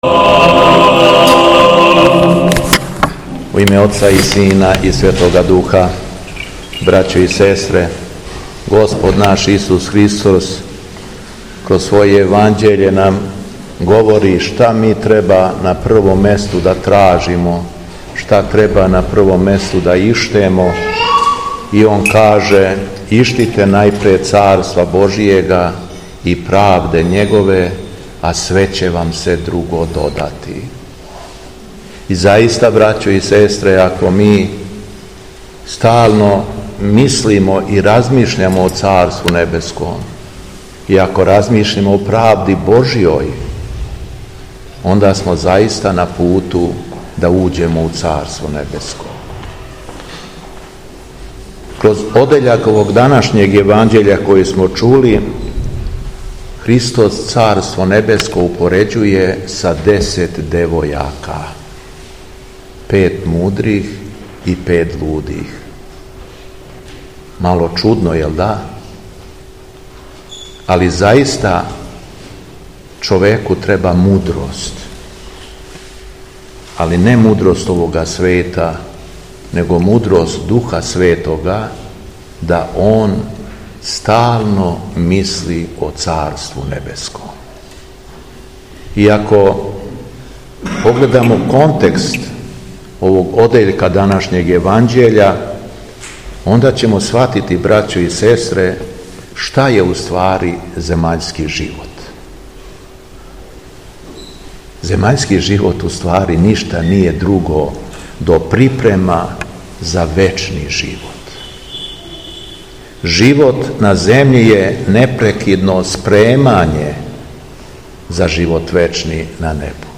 СВЕТА АРХИЈЕРЕСЈКА ЛИТУРГИЈА У ХРАМУ СВЕТОГ АРХАНГЕЛА ГАВРИЛА У АРАНЂЕЛОВЦУ
Беседа Његовог Високопреосвештенства Митрополита шумадијског г. Јована
Дана 4. октобра 2025. године, на дан празновања оданија Воздвижења Часнога Крста и Светог апостола Кодрата, Његово Високопреосвештенство Митрополит шумадијски Господин Јован је у Аранђеловцу у храму посвећеном Светом архангелу Гаврилу служио Свету Архијерејску Литургију.